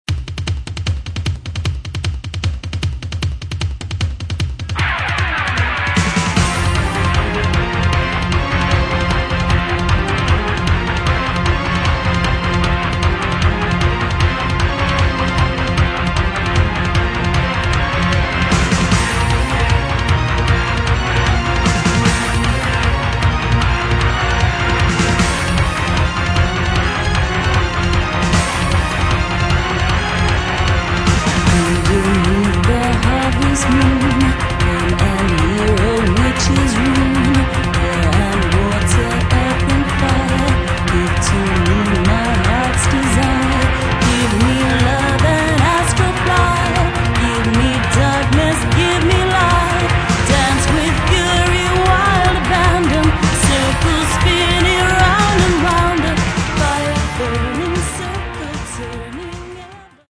Рок
демонстрирует более мрачную и задумчивую сторону группы
вокал, флейта
гитара, программинг, композиция, вокал